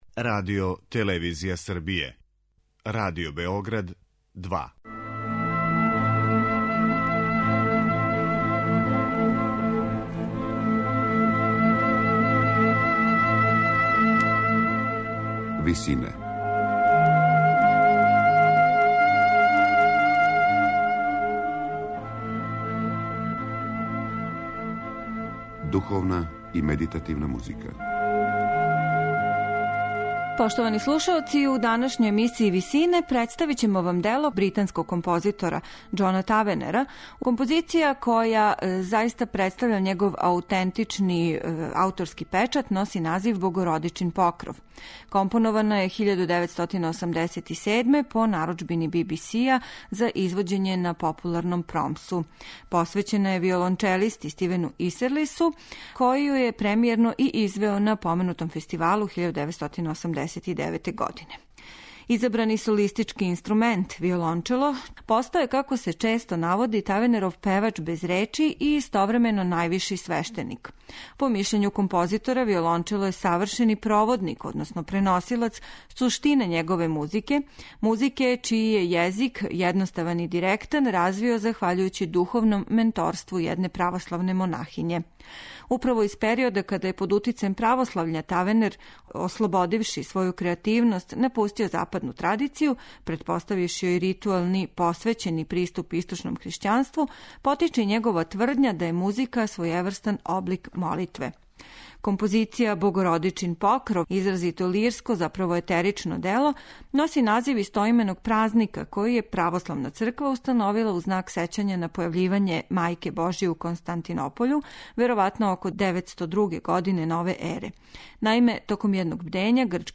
оркестар
медитативне и духовне композиције